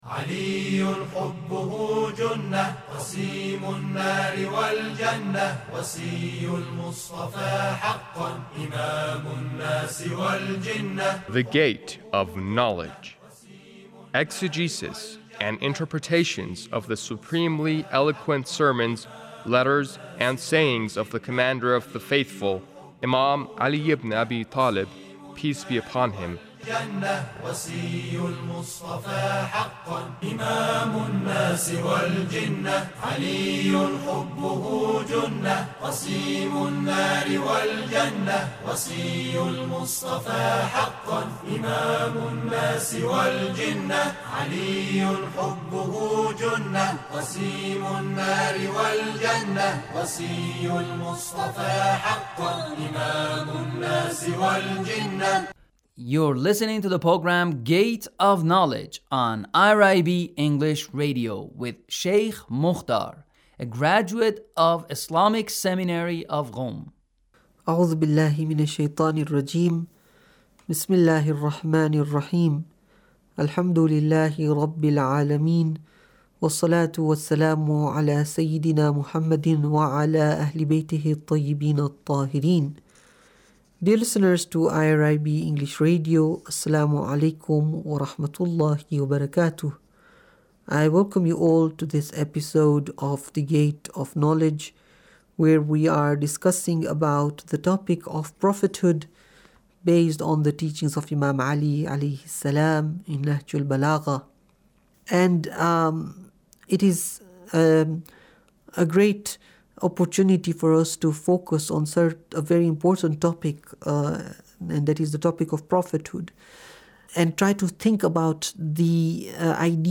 Sermon 2 -